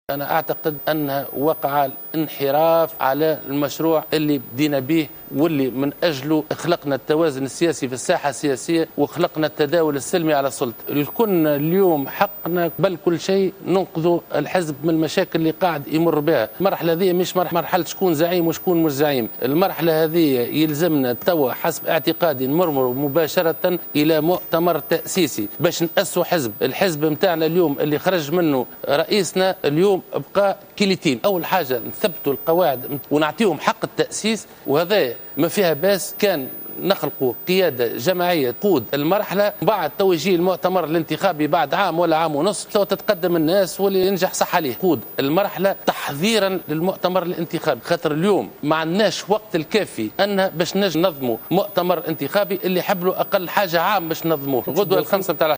وأوضح حافظ قايد السبسي في حوار تلفزي على قناة نسمة بثته مساء اليوم الأربعاء أن هذه المرحلة ليست مرحلة تنازع وصراع عن الزعامة والمناصب بقدر مايجب أن تكون مرحلة بناء وتأسيس للحزب الذي بقي "يتيما" بعد خروج رئيسه على حد تعبيره.